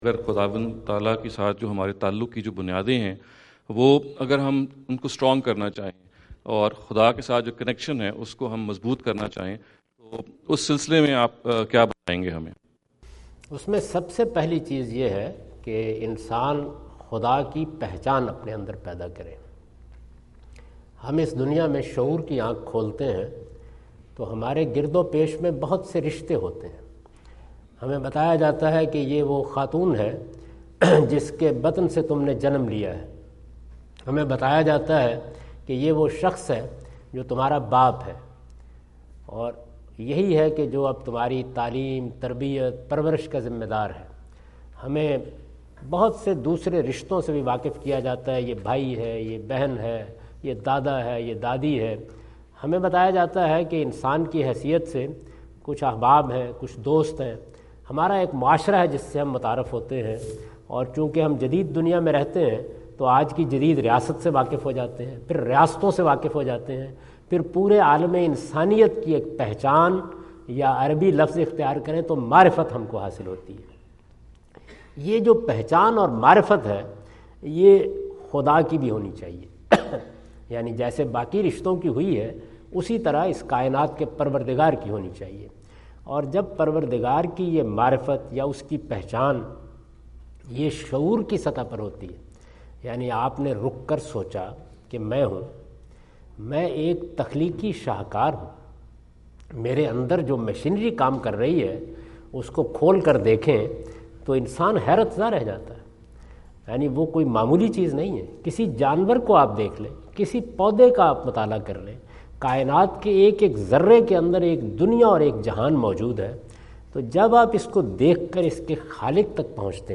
Javed Ahmad Ghamidi answer the question about "Strengthening Connection with God" asked at Aapna Event Hall, Orlando, Florida on October 14, 2017.
جاوید احمد غامدی اپنے دورہ امریکہ 2017 کے دوران آرلینڈو (فلوریڈا) میں "خدا سے مضبوط تعلق" سے متعلق ایک سوال کا جواب دے رہے ہیں۔